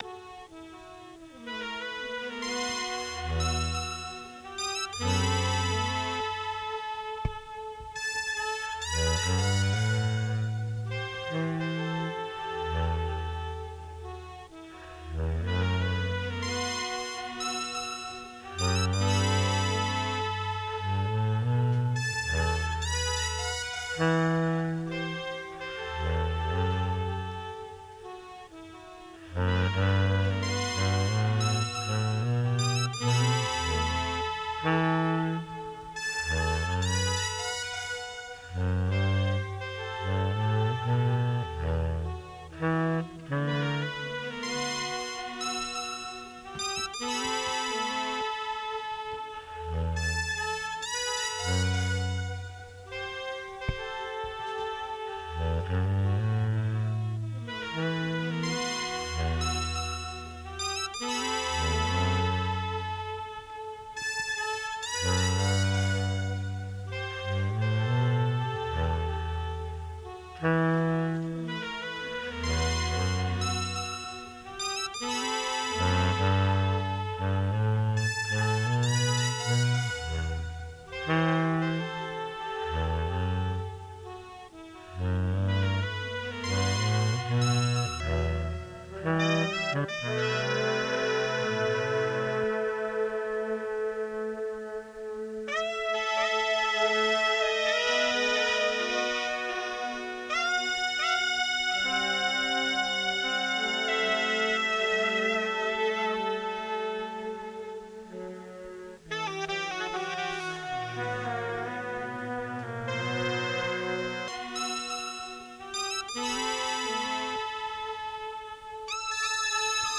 Recorded live at Goodbye Blue Monday, Bushwick, Brooklyn.
drums
amped alto saxophone
Stereo (722 / Pro Tools)